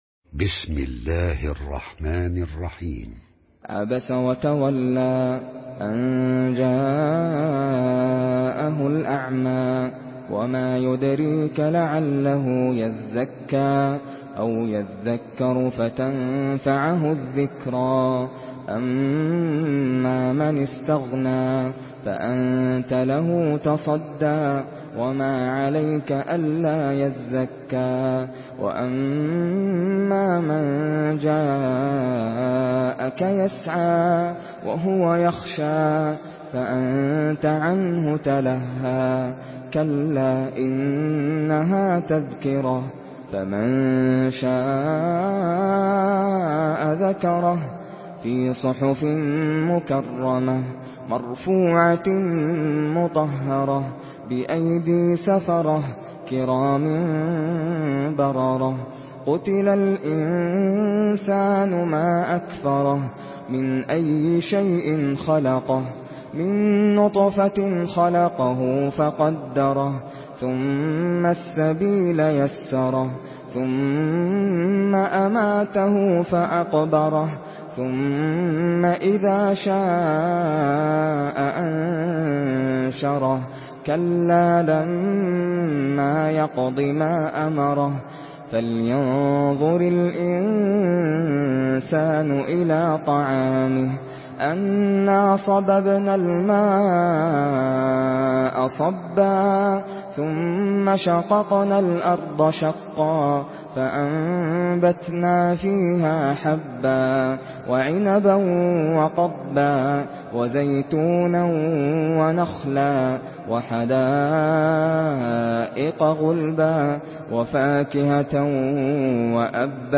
Surah Abese MP3 in the Voice of Nasser Al Qatami in Hafs Narration
Murattal Hafs An Asim